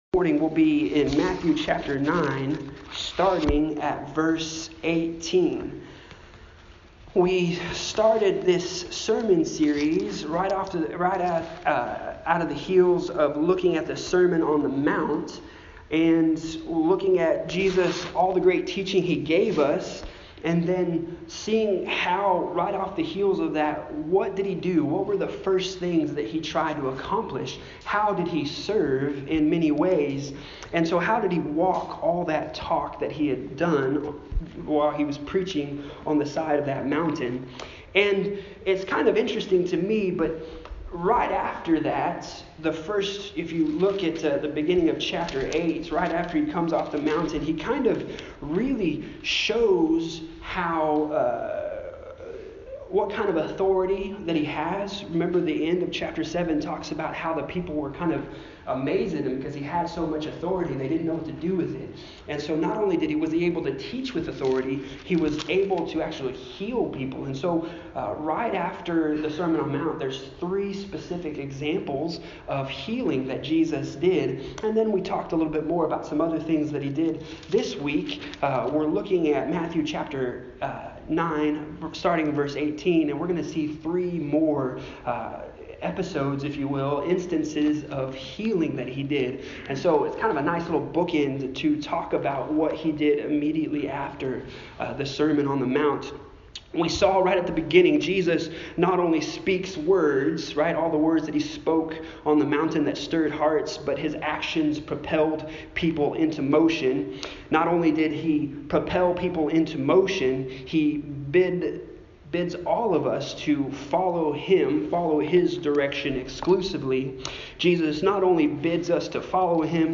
Passage: Matthew 9:18-34 Service Type: Sunday Morning